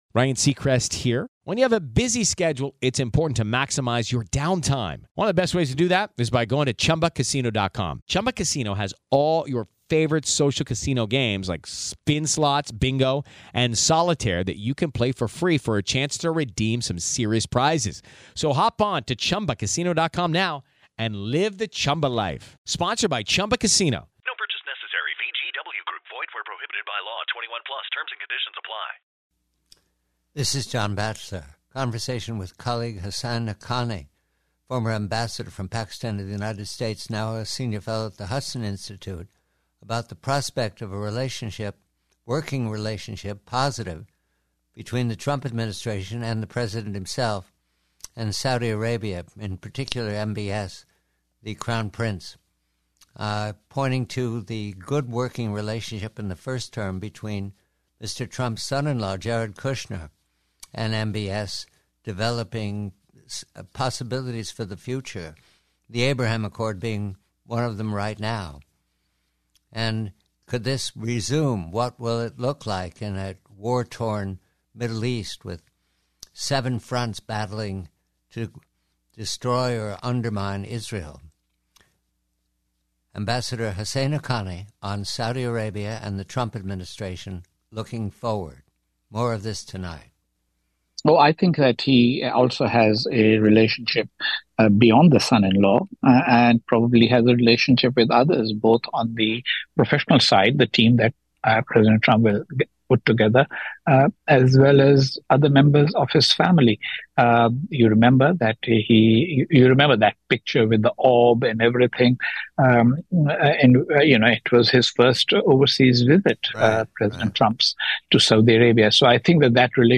PREVIEW: SAUDI ARABIA: Conversation with former Pakistani Ambassador Husain Haqqani of the Hudson Institute regarding expected Saudi-U.S. relations under a potential Trump administration.